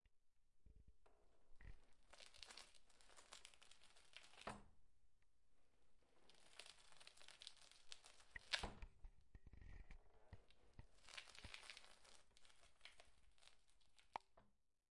11 转轮